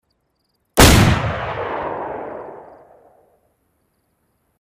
DayZ - SINGLE - BK133 GUN
dayz-single-bk133-gun.mp3